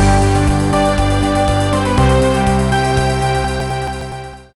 Jingle_Transition.ogg